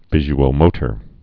(vĭzh-ō-mōtər)